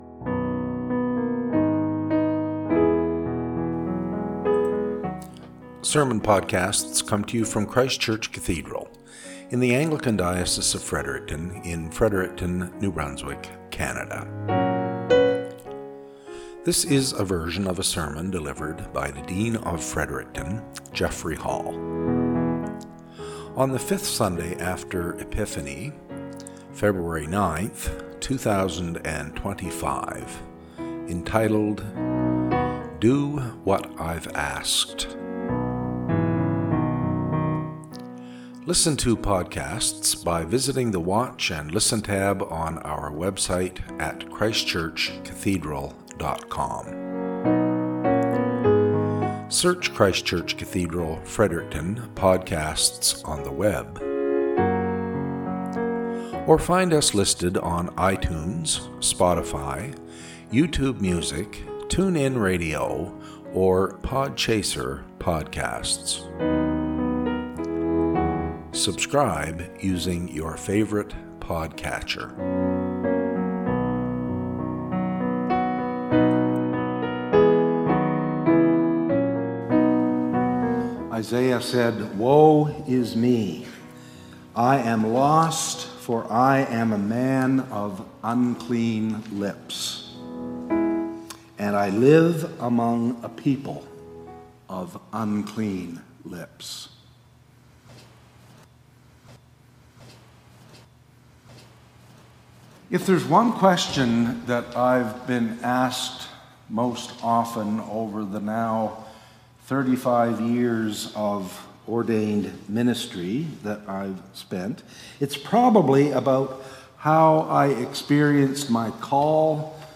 Cathedral Podcast - SERMON -
Podcast from Christ Church Cathedral Fredericton